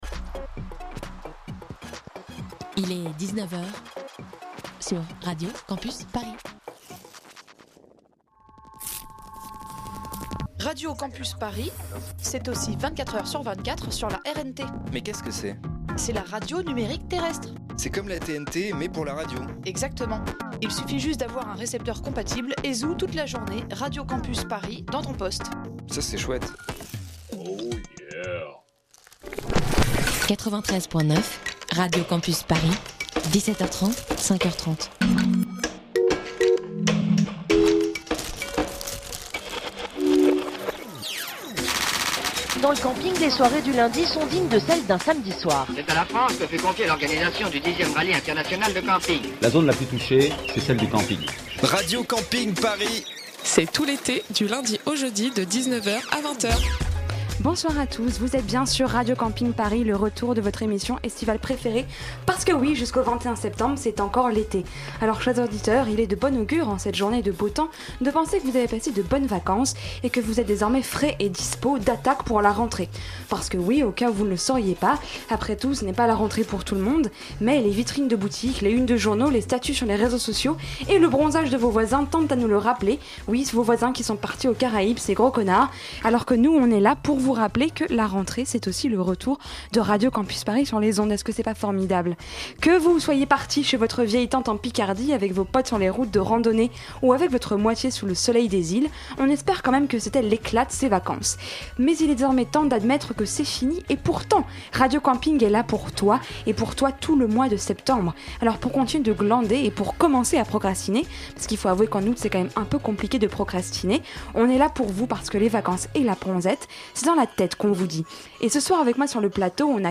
Pour prolonger un peu vos vacances l'émission estivale de Radio Campus Paris repart pour un tour ! Et ce 1er Septembre on vous parle COP 21, la conférence environnementale de Paris qui se déroulera au mois de Décembre, avec la semaine des ambassadeurs et l'interview de Romain Nadal porte-parole du ministère des affair